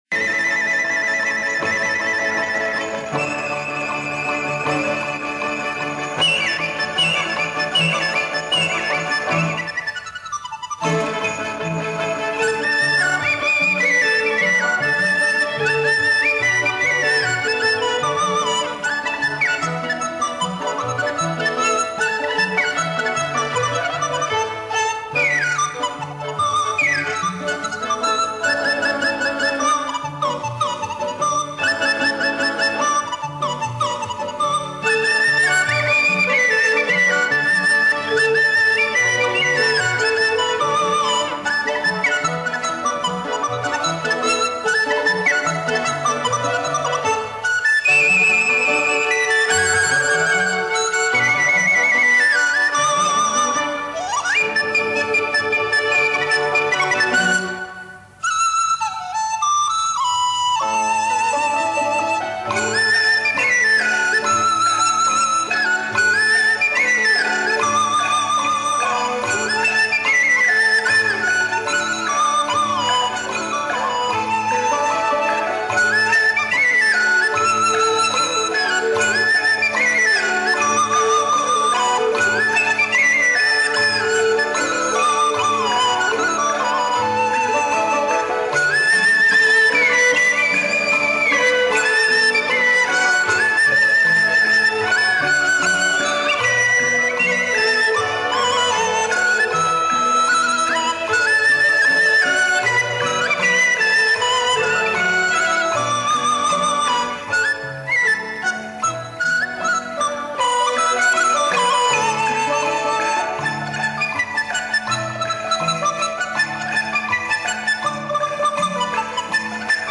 这些笛曲从大量的录音版中精选出来的，它们囊括了当代中国最优秀的笛子演奏家演奏的最有代表性的笛子独奏曲。